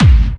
VEC3 Clubby Kicks
VEC3 Bassdrums Clubby 010.wav